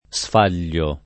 sfaglio [ S f # l’l’o ] s. m.; pl. ‑gli